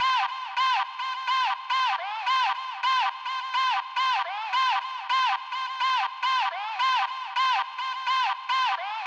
Right Back Vox Loop.wav